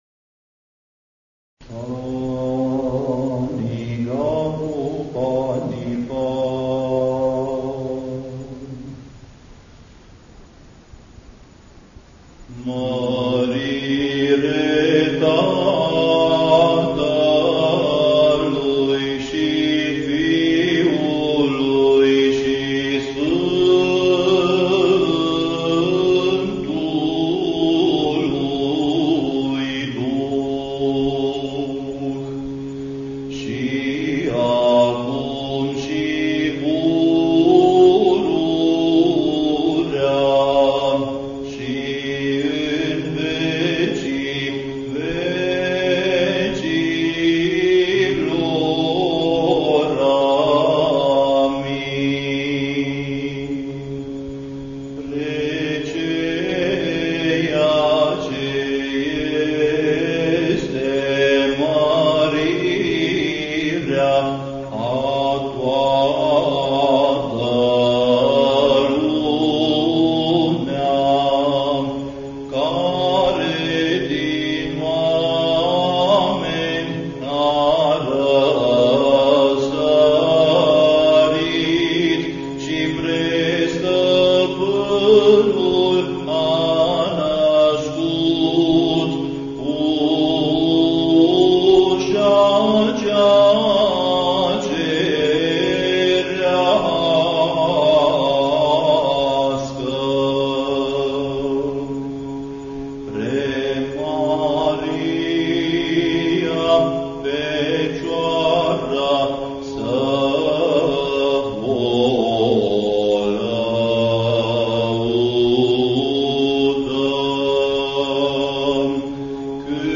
Index of /INVATAMANT/Facultate Teologie pastorala/Muzică bisericească și ritual/Dogmaticile
01. Dogmatica glasul 1.mp3